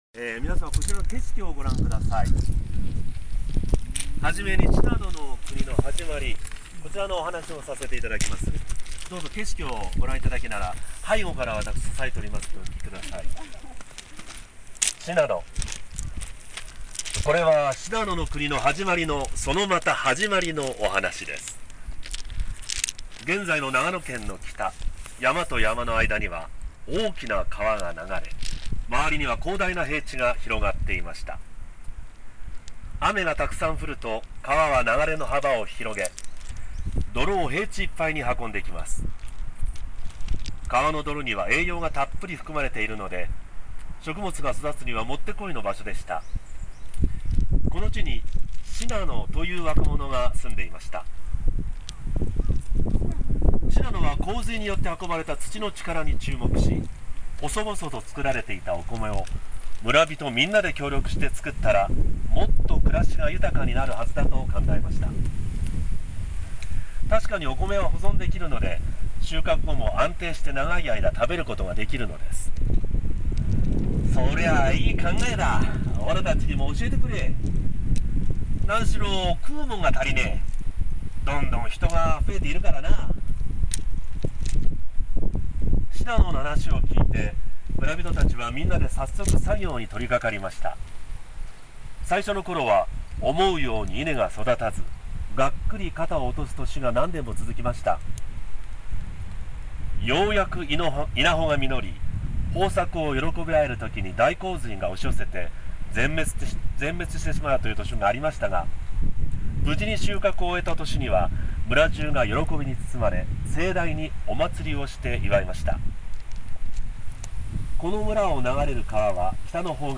バスの定員の関係で３グループに分け、初日の２５日はまず森将軍塚古墳の頂上に（約４９０㍍）に上り、新作民話「シナノ」を聞いてもらいました（朗読は千曲朗読の会）。
次をクリックすると、千曲朗読の会の朗読（約３分）がお聞きになれます。